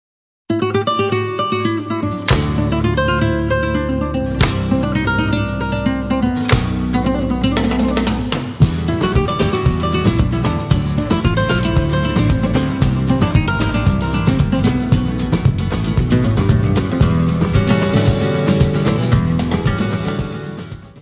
guitarra, voz e palmas
: stereo; 12 cm
Music Category/Genre:  World and Traditional Music